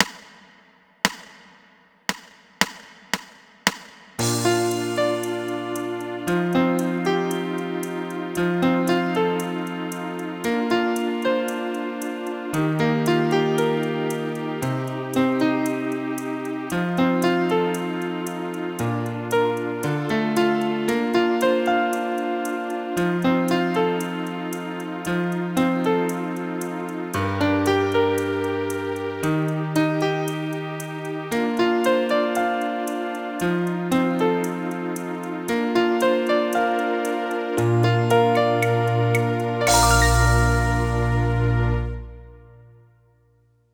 Etüde in C-Dur für Trompete.